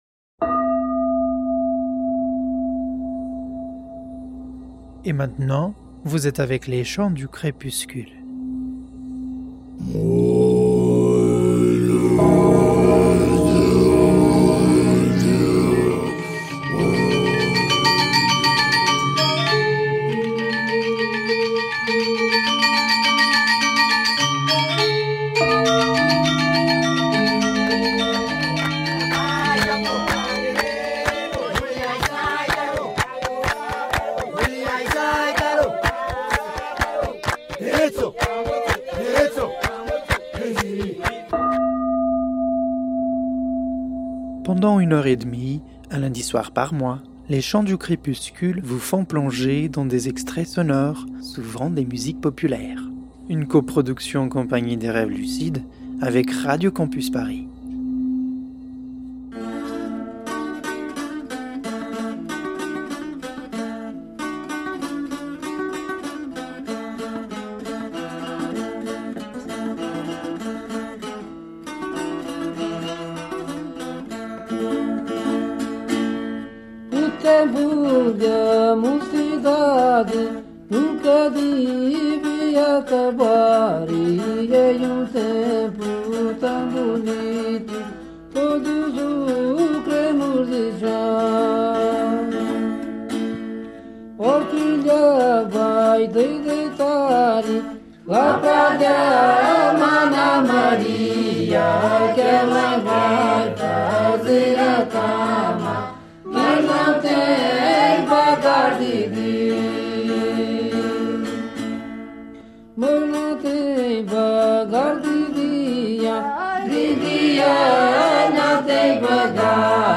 podcast-les-musiques-georgie-1f4a1afc.mp3